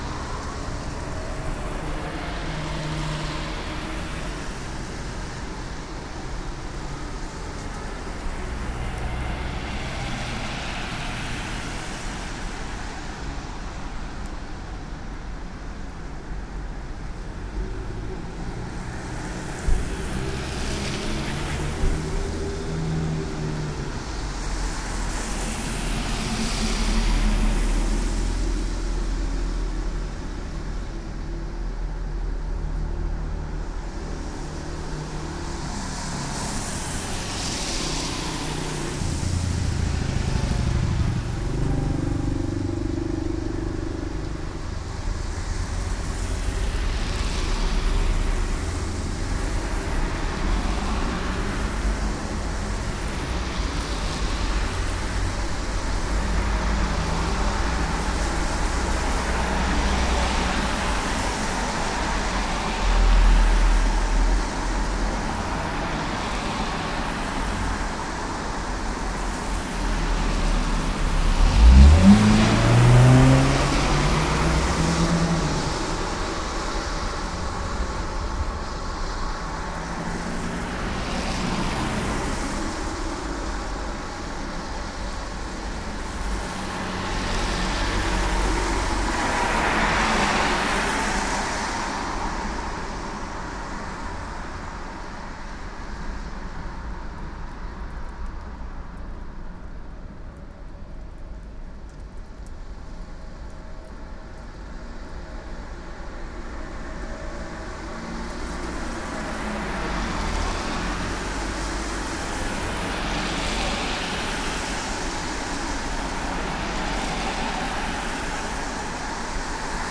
濡れてる車道１
wet_road1.mp3